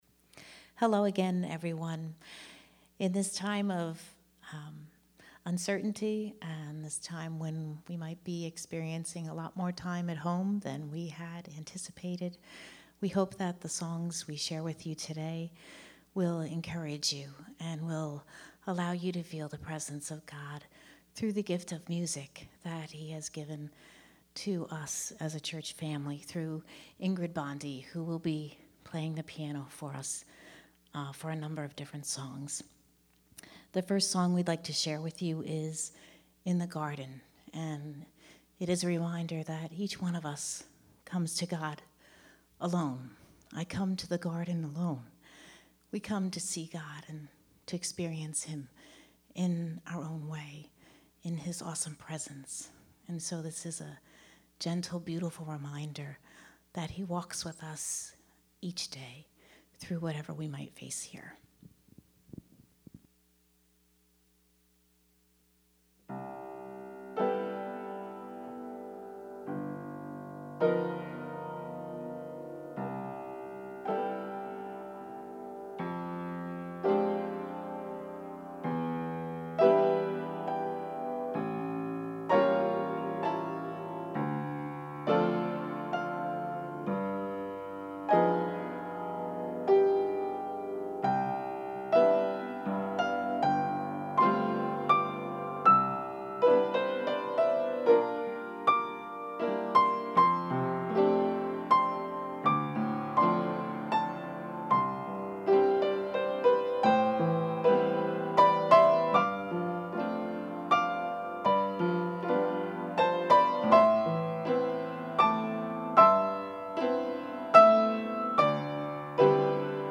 Ministry in Music